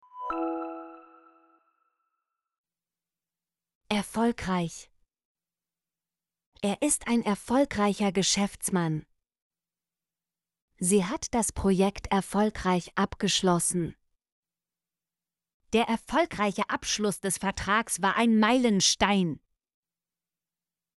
erfolgreich - Example Sentences & Pronunciation, German Frequency List